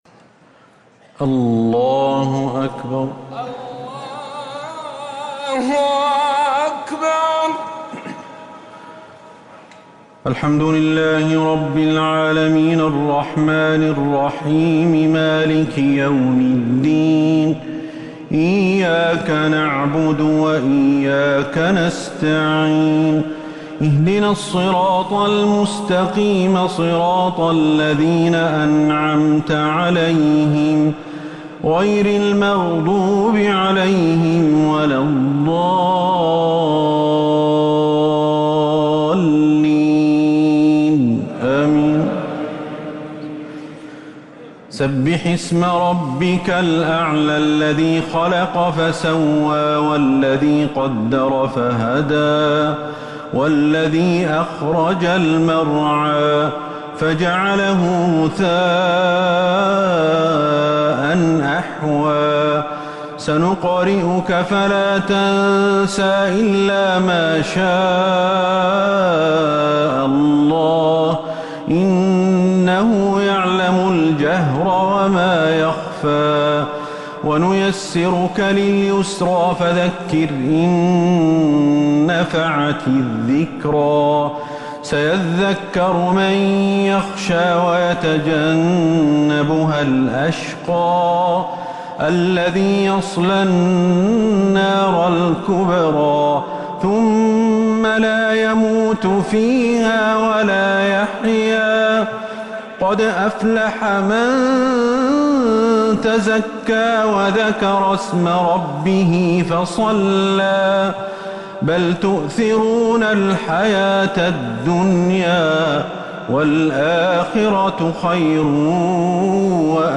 صلاة الشفع و الوتر ليلة 4 رمضان 1447هـ | Witr 4th night Ramadan 1447H > تراويح الحرم النبوي عام 1447 🕌 > التراويح - تلاوات الحرمين